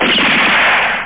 GUNSHOT2.mp3